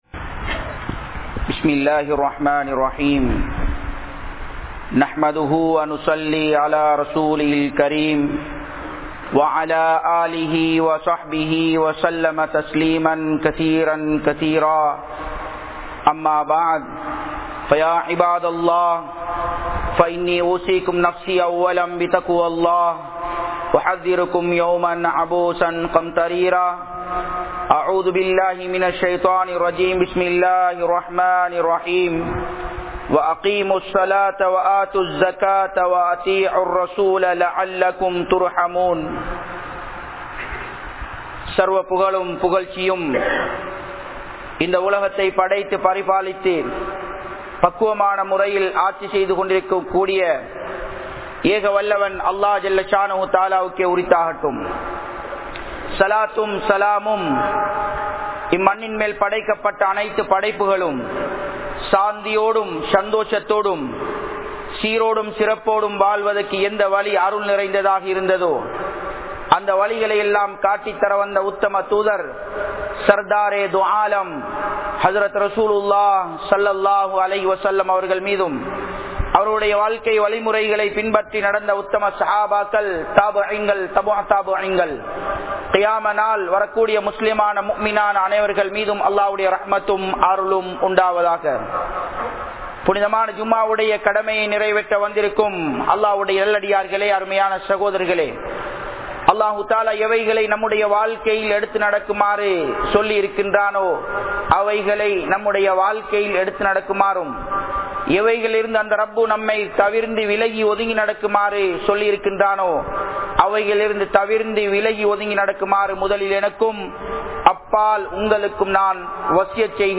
Zakath Koduppathan Sirappuhal(ஸகாத் கொடுப்பதன் சிறப்புகள்) | Audio Bayans | All Ceylon Muslim Youth Community | Addalaichenai